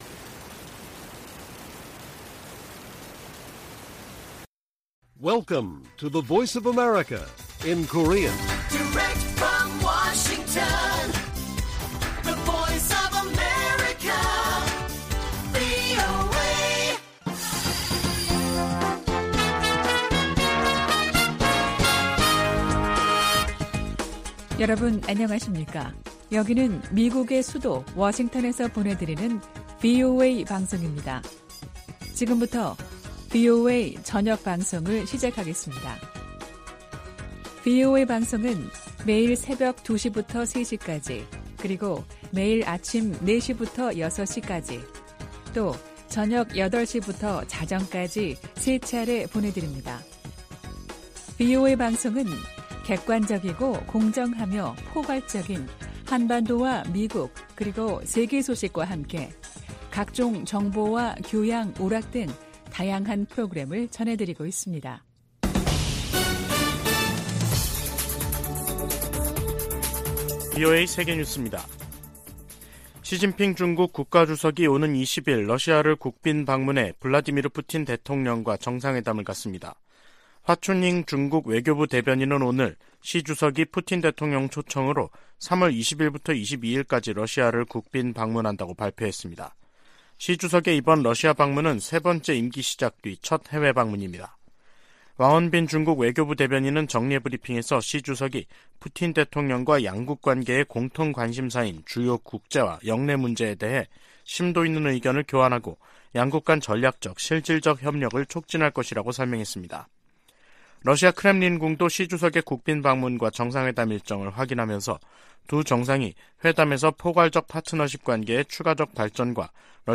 VOA 한국어 간판 뉴스 프로그램 '뉴스 투데이', 2023년 3월 17일 1부 방송입니다. 백악관이 16일 열린 한일 정상회담에 적극적인 환영과 지지 입장을 밝혔습니다. 북한은 16일 대륙간탄도미사일 (ICBM) '화성-17형' 발사 훈련을 실시했다고 밝히고, 그 신뢰성이 검증됐다고 주장했습니다. 미 국방부는 북한의 지속적 도발에도 불구하고 대북 억제력이 작동하고 있다고 강조했습니다.